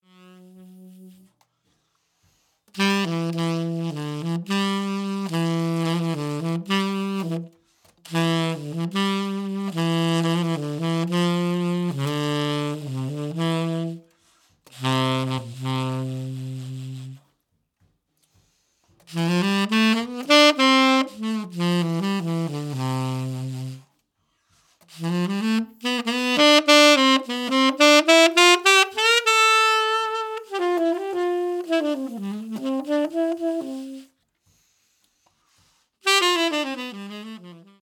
solounacc.mp3